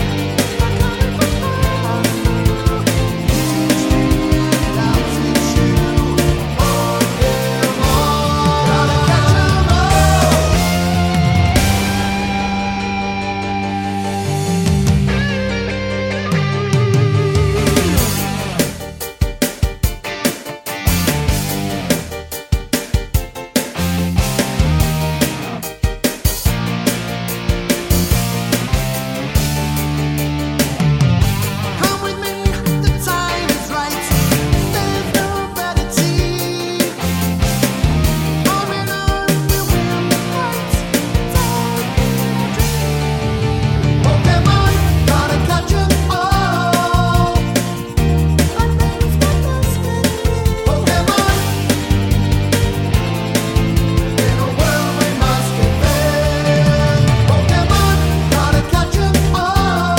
no Backing Vocals